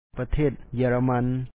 pathèet jǝǝlamán Germany